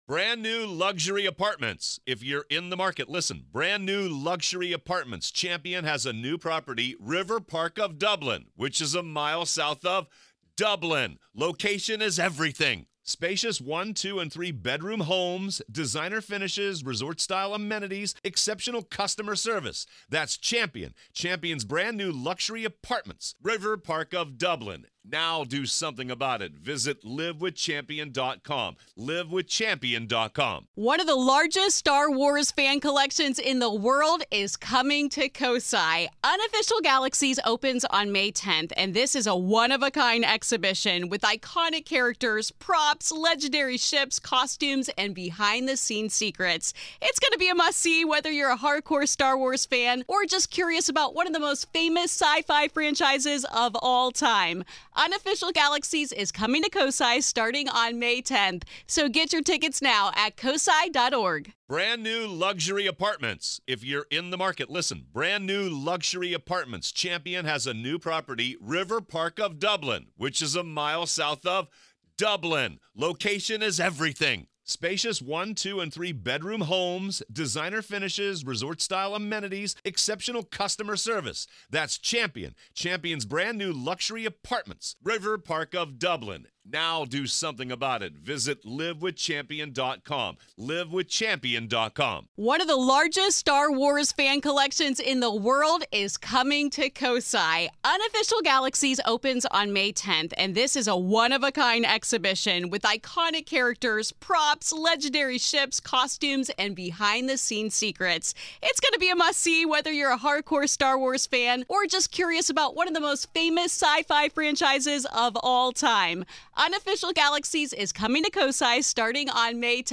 I am joined by legendary reporter George Knapp for nearly two hours to discuss;